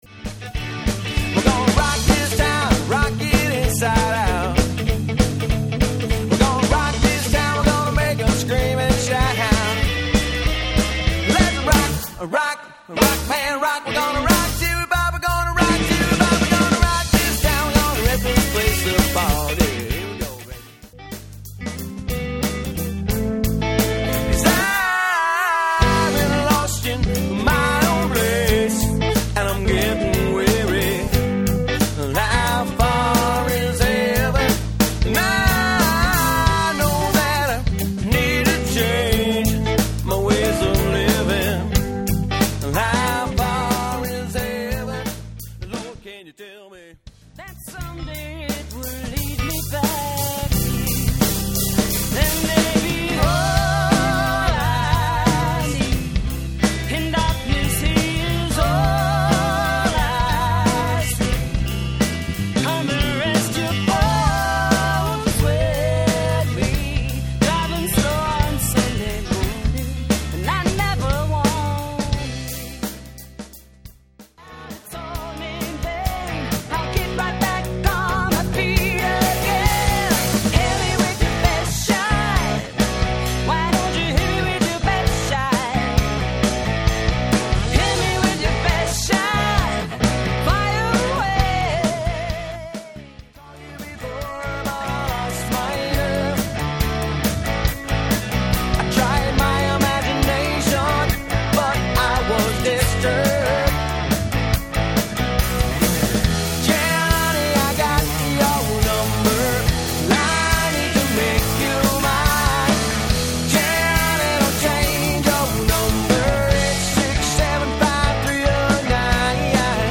variety band